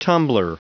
Prononciation du mot tumbler en anglais (fichier audio)
tumbler.wav